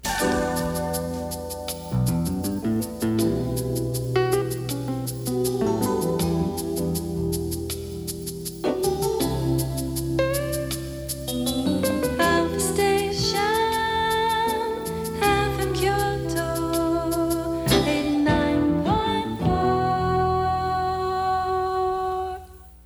音源は全てステレオ録音です。
全体を通しての感想ですが、ジャズを基調としたとても贅沢な楽曲となっています。